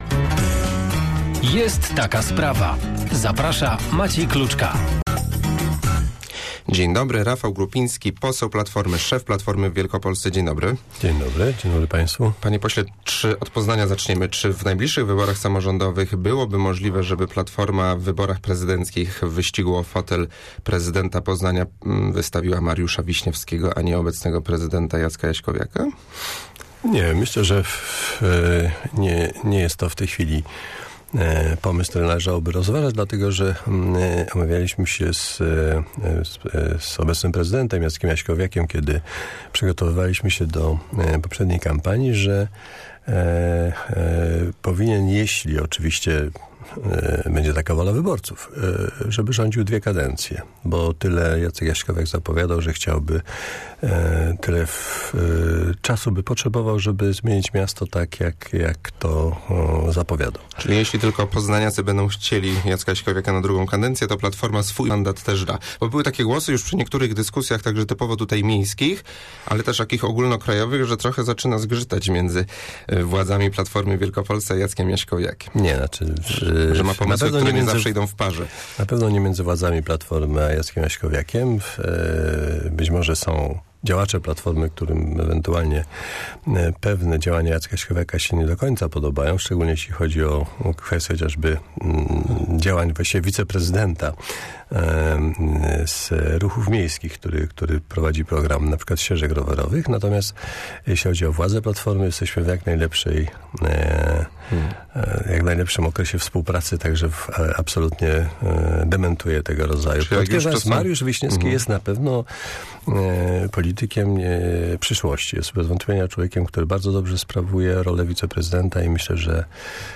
"Kompromis w sprawie publikacji wyroku Trybunału Konstytucyjnego z 9 marca jest ciągle możliwy" - powiedział rano na antenie Radia Merkury przewodniczący Platformy Obywatelskiej w Wielkopolsce poseł Rafał Grupiński.
(cała rozmowa poniżej)